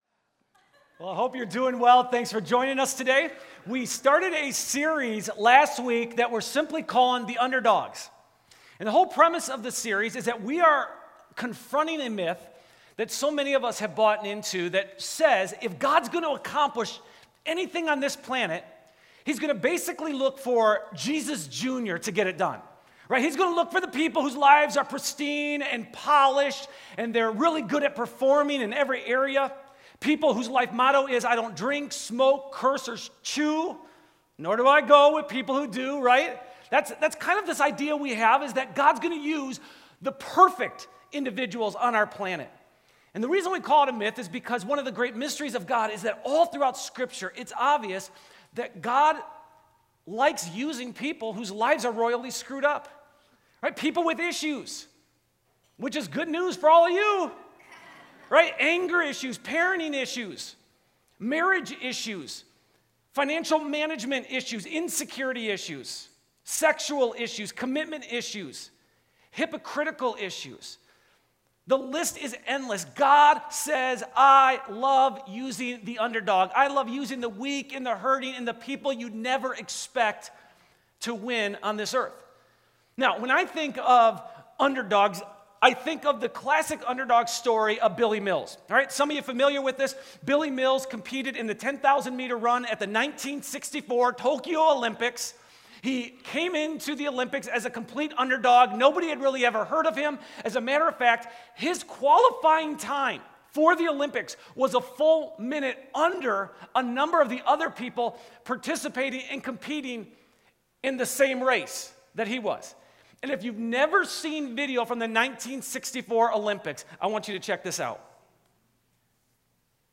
6-28-14+Sermon.m4a